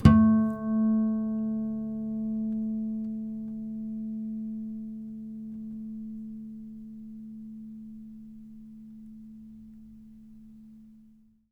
harmonic-03.wav